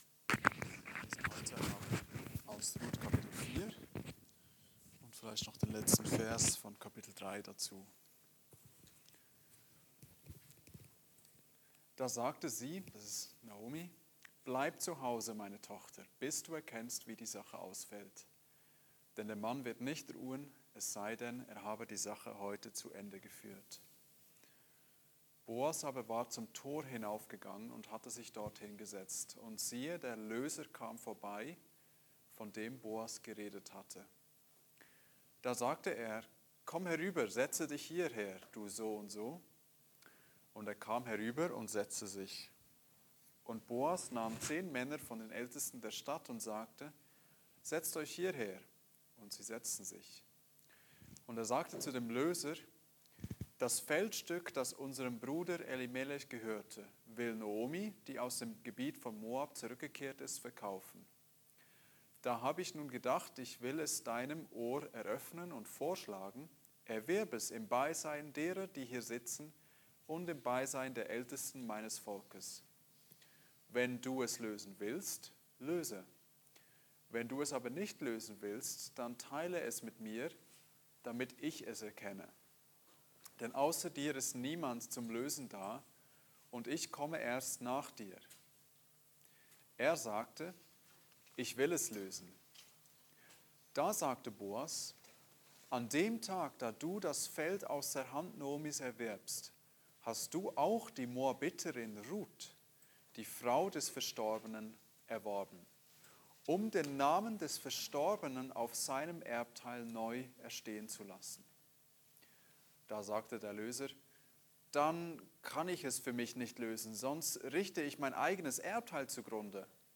Die Ehre des Namens (Andacht Gebetsstunde)